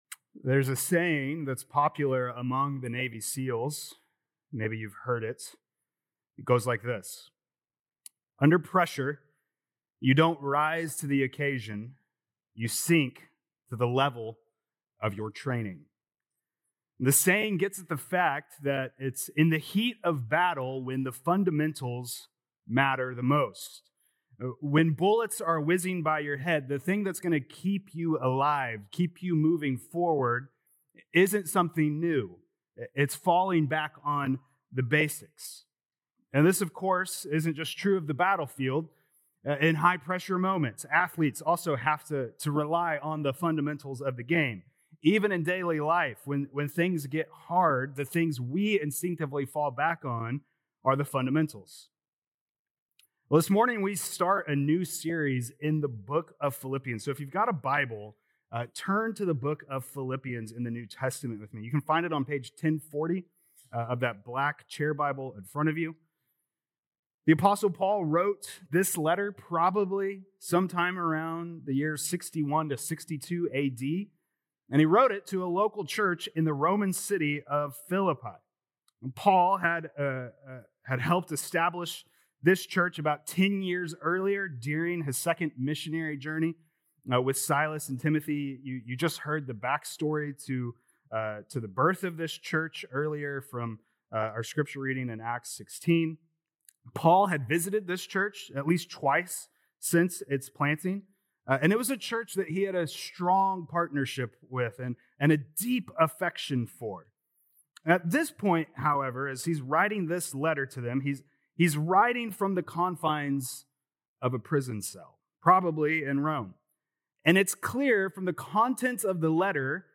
June 15th Sermon | Philippians 1:1-2